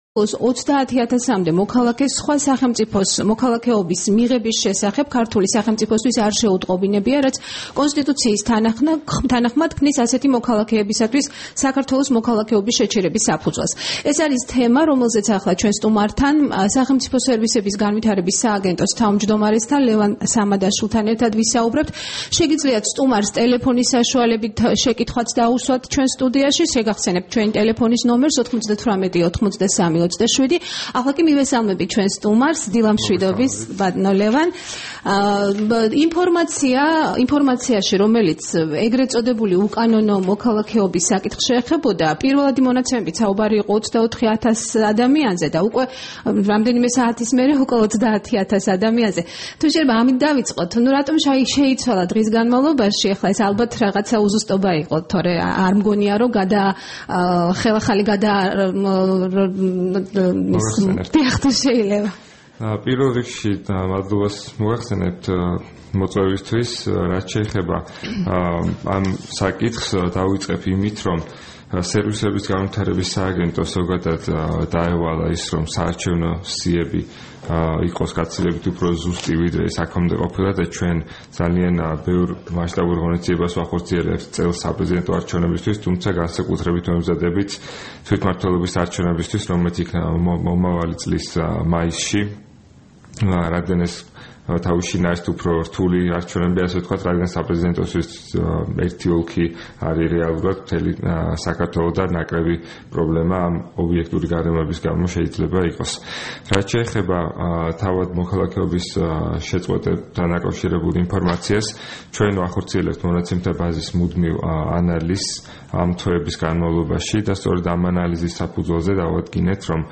რადიო თავისუფლების დილის გადაცემის სტუმარი იყო სახელმწიფო სერვისების განვითარების სააგენტოს თავმჯდომარე ლევან სამადაშვილი.
საუბარი ლევან სამადაშვილთან